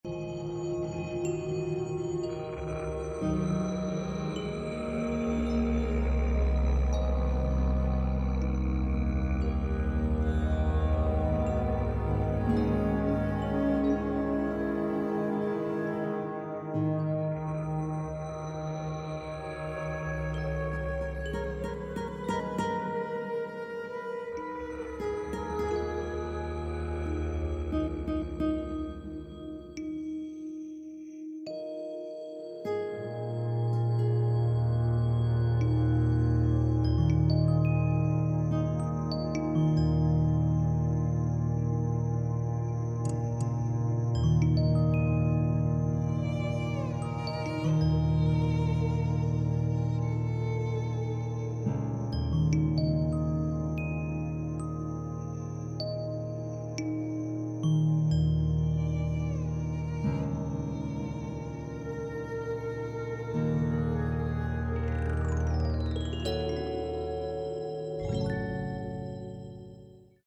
I started writing instrumental music.
Musically I wanted to project a sense of amorphous motion at the start, turning into a loose walking rhythm.
I wanted it to be beautiful and a bit frightening but somehow calm throughout.
I used digital samplers to produce sounds of electric piano, cello, guitar, violin and bass. Once I had the tracks basically where I wanted them I began to finesse them with various software effects and a variety of different mixes.